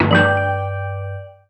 ThrowOffStun.wav